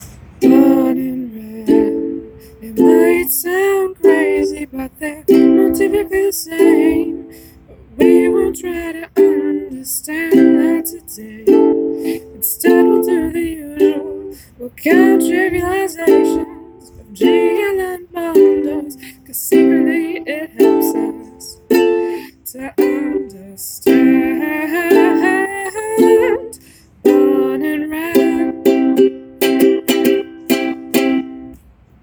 Ukulele Songs from the TamagaWHAT Seminar